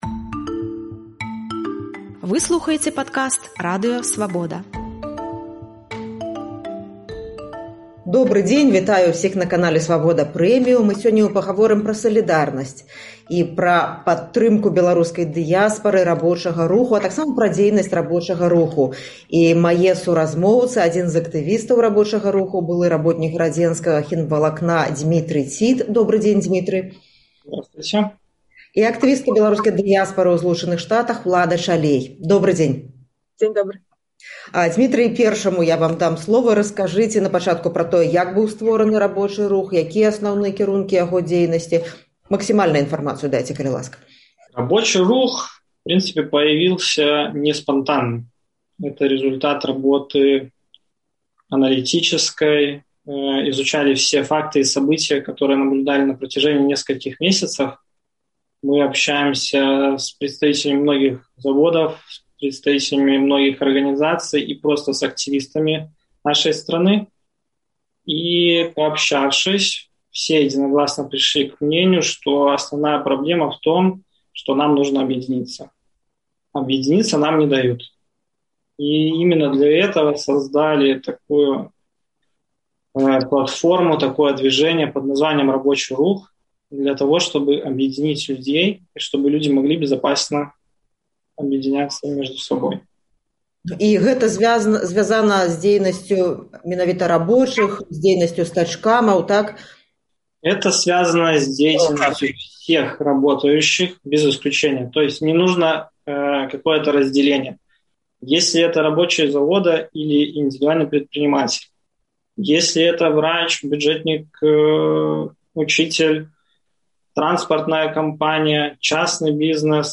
Абмяркоўваем з актывістам руху